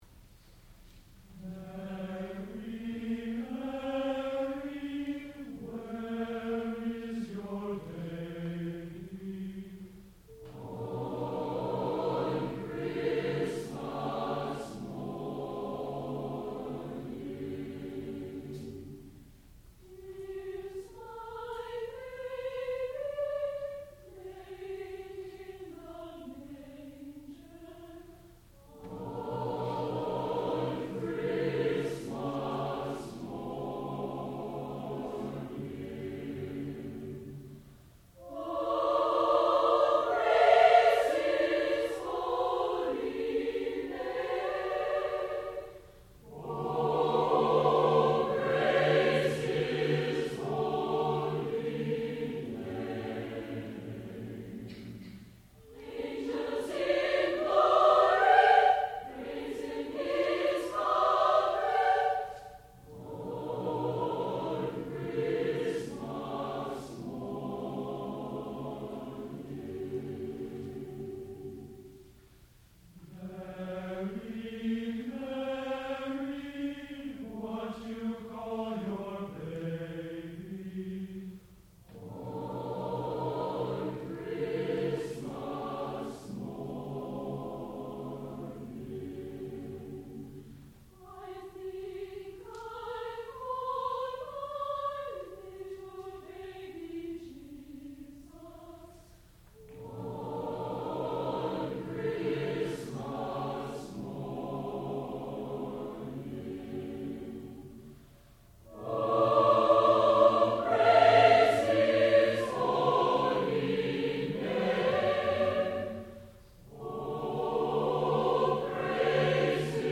sound recording-musical
classical music